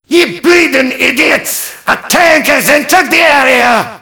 mvm_tank_alerts02.mp3